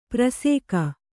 ♪ prasēka